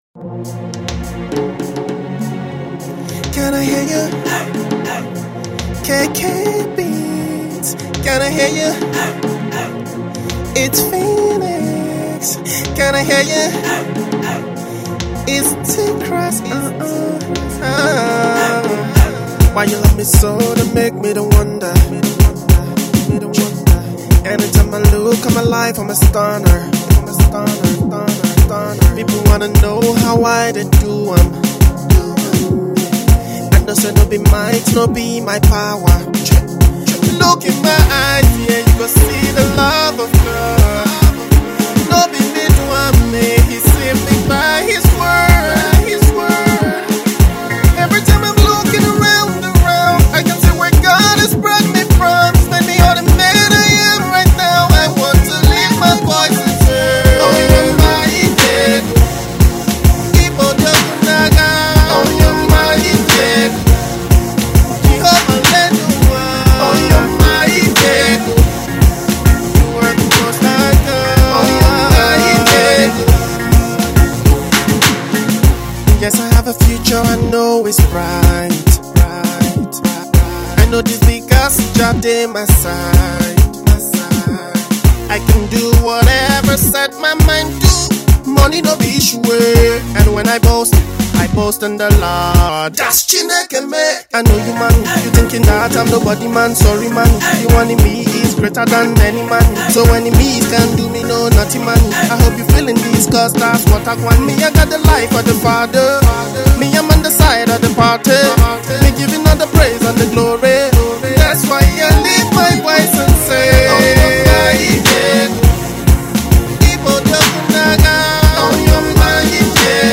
afro/pop
pop, upbeat andbrhythmical tempo